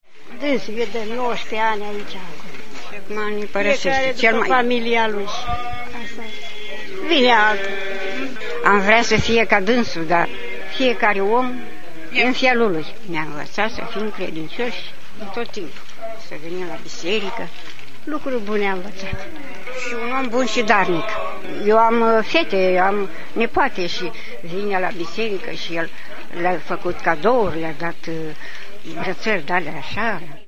Enoriasii din Cosambesti au sarbatorit astazi hramul bisericutei din vatra satului, folosita in ultimii ani doar la slujbe de inmormantare.